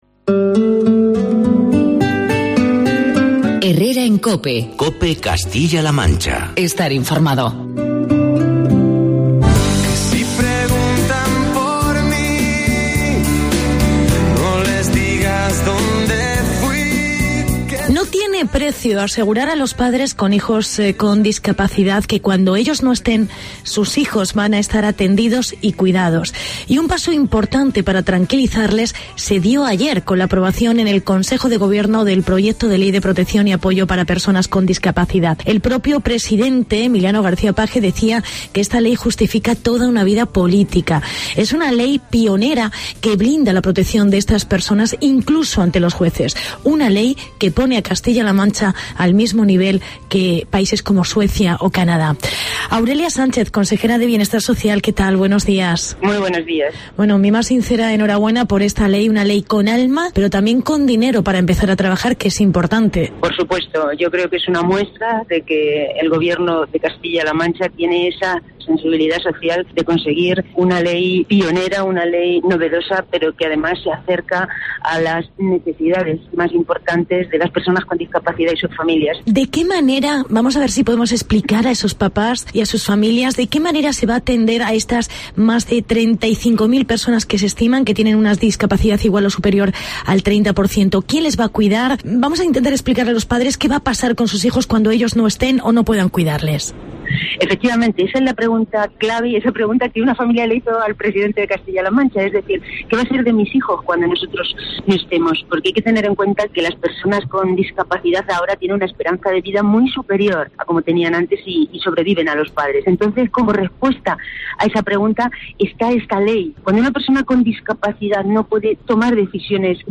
Entrevista a la Consejera de Bienestar Social. Aurelia Sánchez